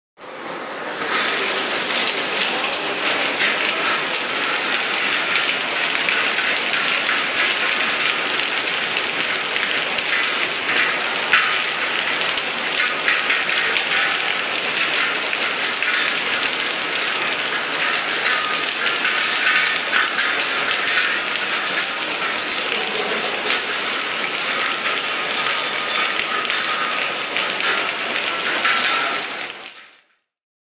描述：Alta fidelidad：grabaciónrealizadadesde el edificio Orlando Sierra de la Universidad de Caldas（马尼萨莱斯）。 El audio Corresponde al sonido de la lluvia que cae fuertemente y golpea en elpasamanometálicodela rampa del edificio。 2016年4月，我们将了解更多信息。 高保真度：录制自奥兰多塞拉利昂大学卡尔达斯大学（马尼萨莱斯）。音频对应于雨声严重下降并撞击建筑物斜坡的金属扶手。录音是用三星三星制作的，并于2016年2月4日注册。
声道立体声